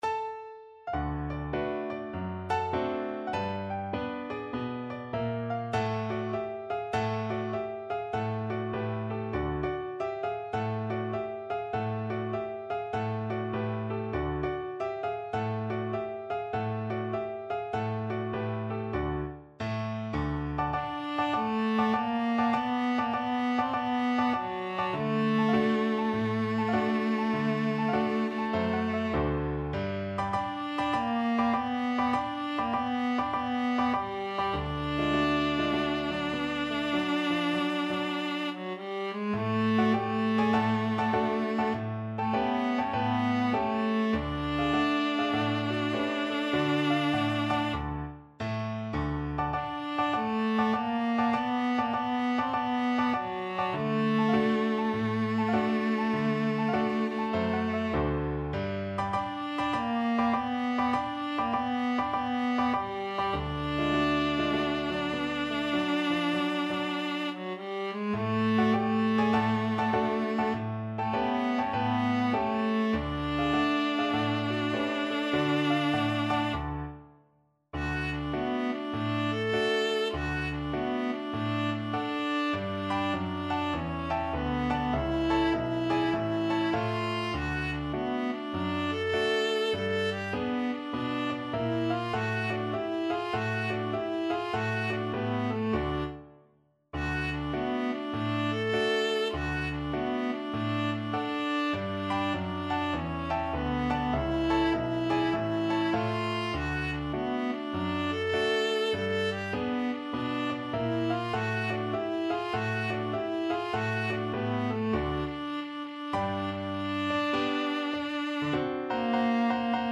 Jazz (View more Jazz Viola Music)